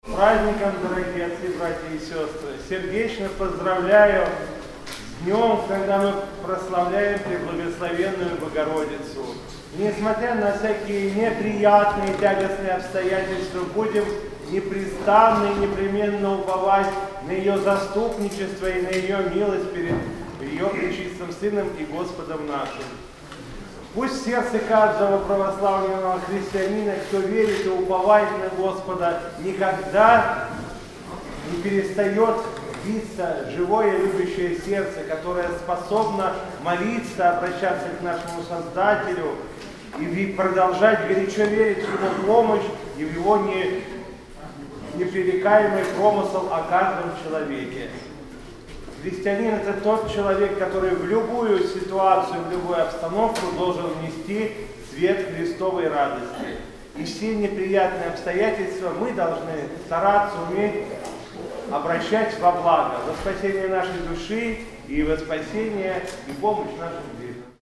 По окончании богослужения владыка Игнатий обратился к присутствующим с архипастырским словом: